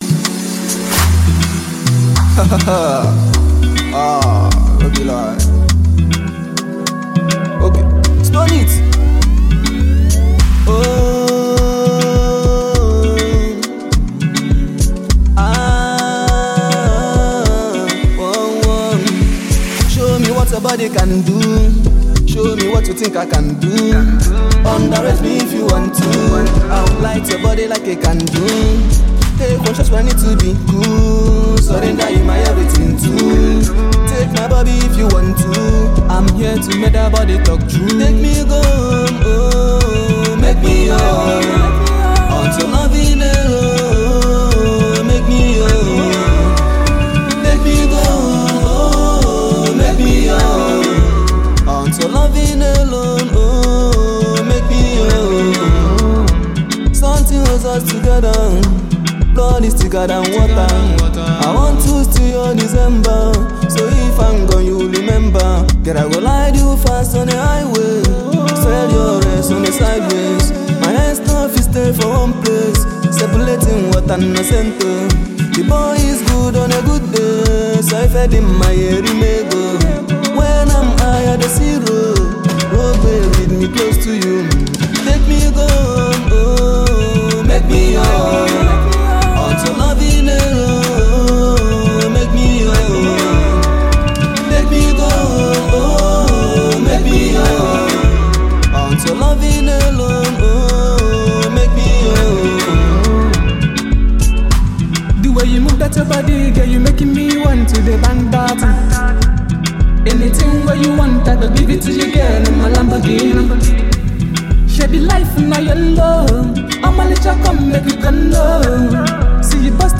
soulful and captivating sound